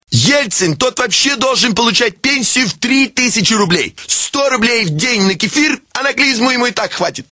Категория: Рингтоны пародии